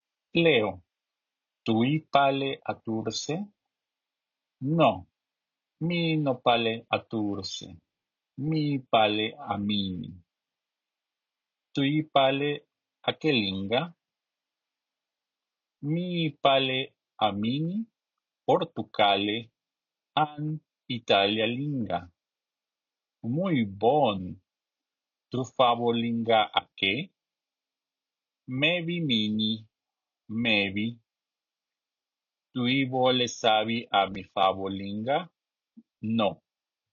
Let’s see a conversation: